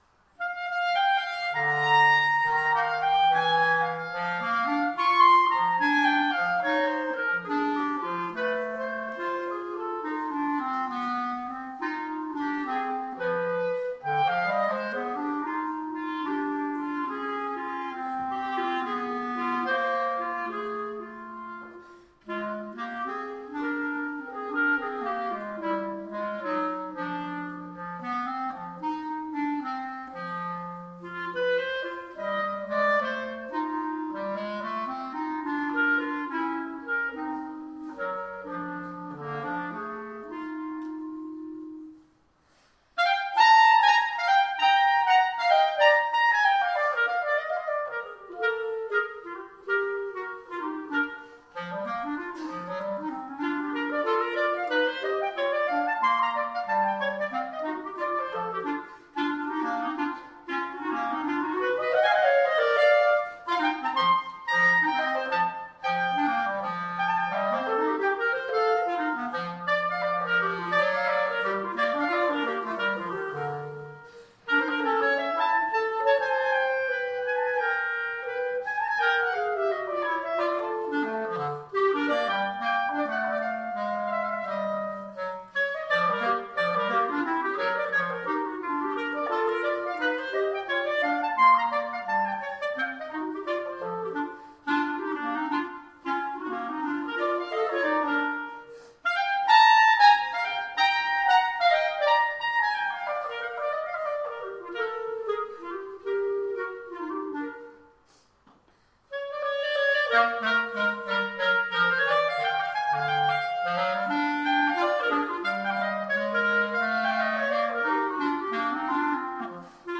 They are from the coffee house event we held as a fundraiser at Mount Calvary back in may of 2010, so they’re about 3 years old, but just now seeing the light of day.
clarinet